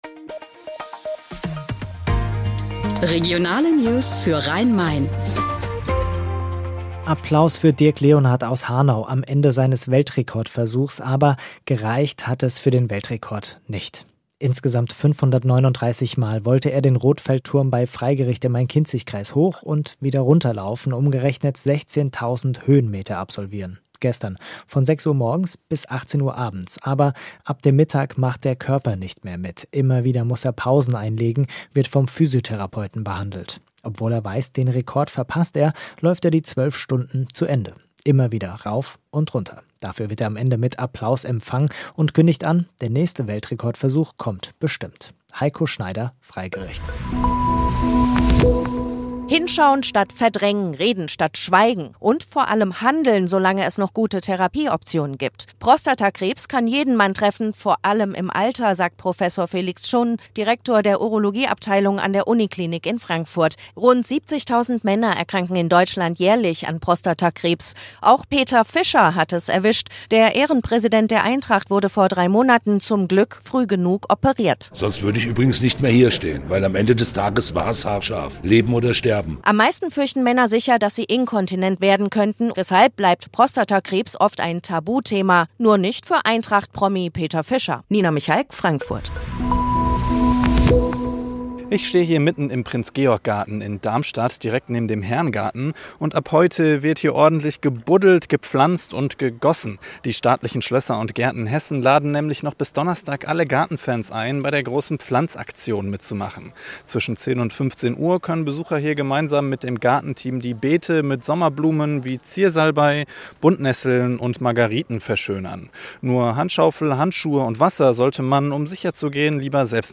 Mittags eine aktuelle Reportage des Studios Frankfurt für die Region